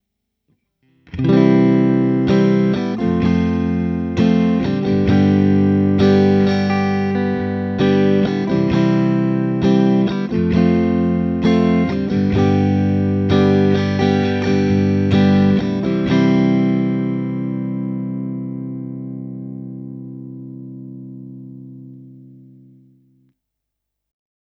2. C - F - G  (I - IV - V)
2.-C-F-G.wav